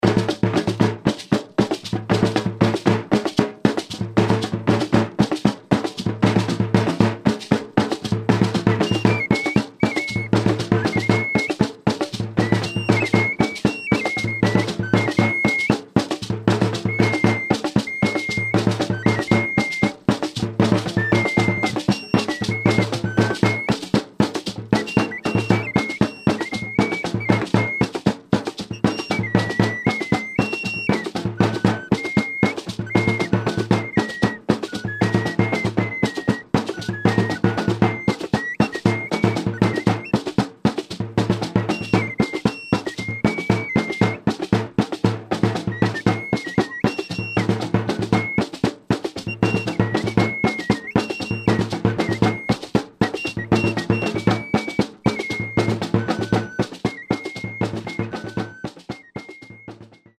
The gingaung is a double-skin drum used by the Bimoba. It belongs to a family of three similar drums, i.e. the gingaung, the gingaung bik and the gingaung pang, of which the gingaung is the bass drum and the largest of the three. It has a snare at the bottom and the membrane at the top is struck with a drumstick and damped with the palm or the fingers.
Its deep tone gives an extra dimension to the music by playing ostinato figures that have a fairly repetitive rhythmical pattern.